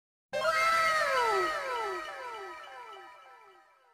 Perfect For Unblocked Sound Buttons, Sound Effects, And Creating Viral Content.